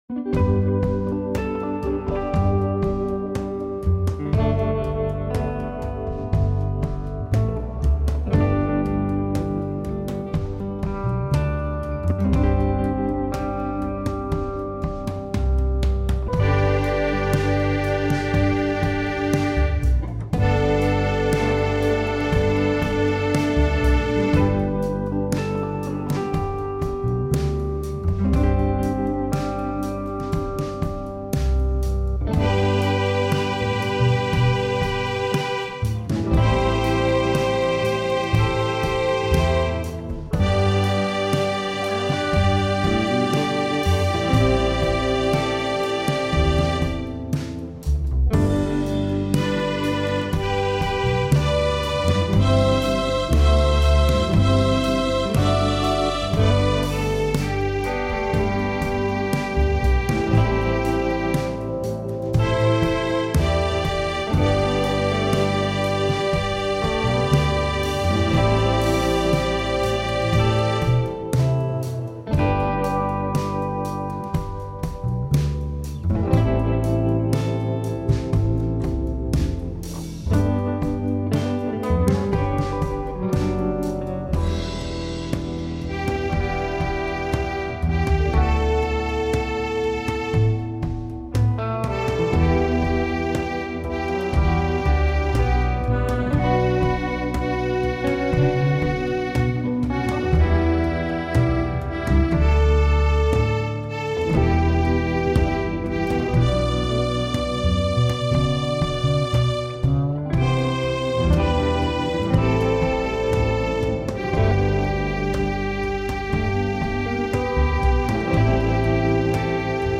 My backing leaves in most of the harmony in the refrain.